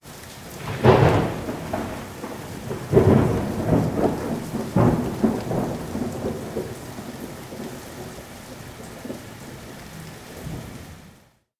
WEATHE R现场录音 " 00007 grzmot 7
描述：有雨的小风暴，高出路面50厘米，单声道，由AKG c414 TLII
Tag: 闪电 风暴